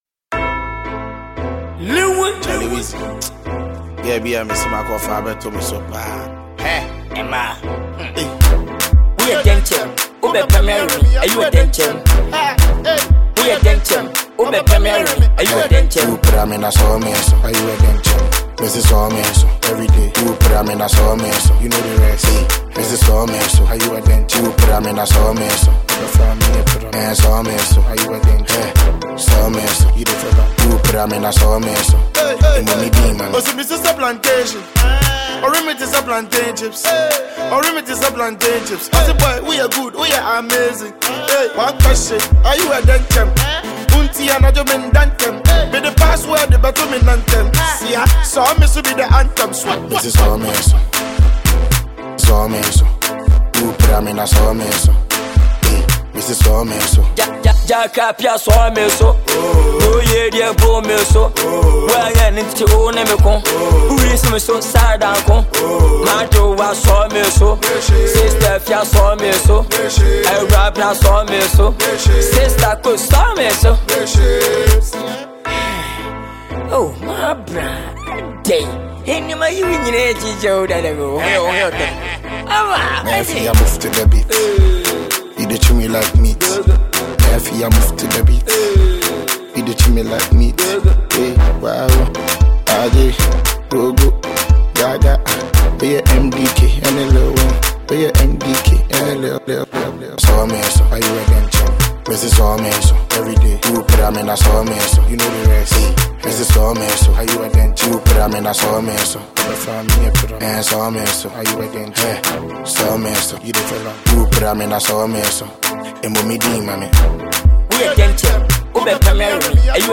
GHANA MUSIC
sultry verse